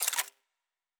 Weapon 12 Foley 3 (Laser).wav